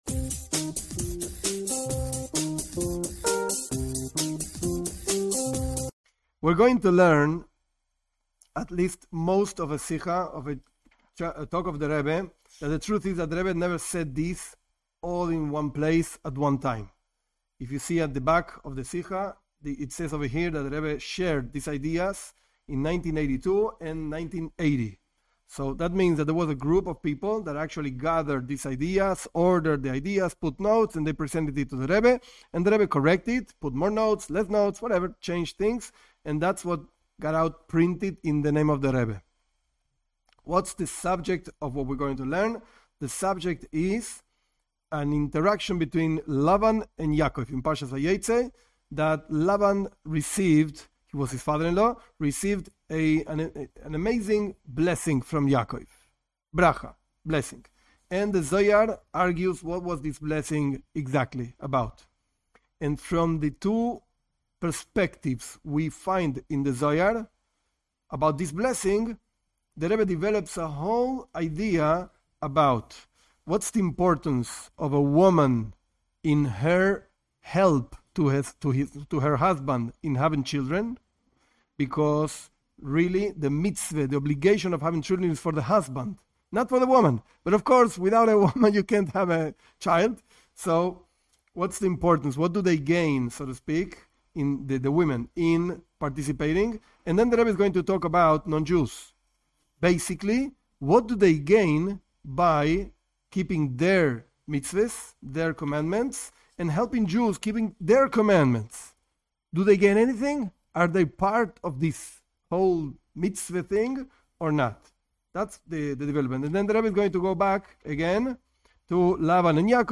This class is parts of a talk by the Rebbe on the blessings that Lavan received because of Yaakov.